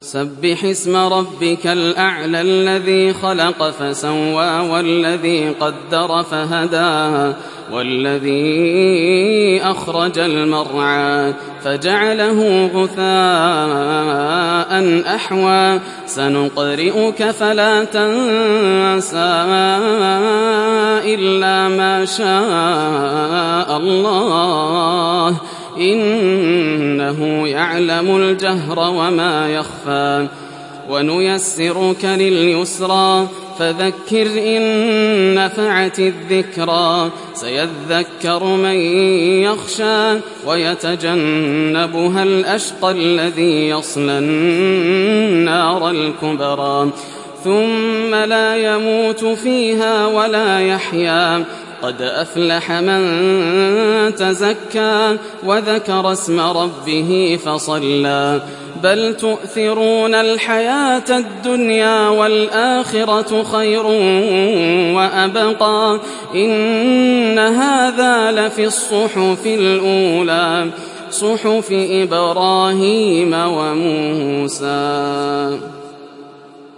Surat Al Ala mp3 Download Yasser Al Dosari (Riwayat Hafs)